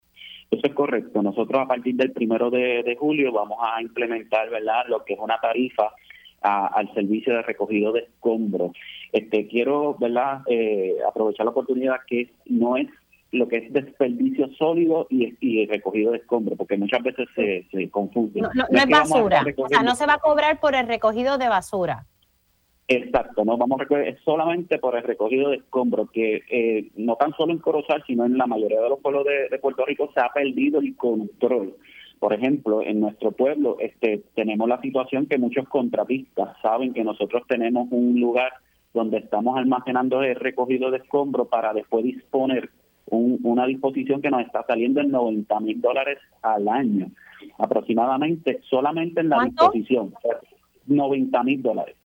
El alcalde de Corozal,  Luis “Luiggi” García informó en Pega’os en la Mañana que debido a los apretos económicos que enfrenta el municipio, comenzarán a implementar una tarifa por servicios de recogido y depósito de escombros y material vegetativo, a partir del próximo 1 de julio.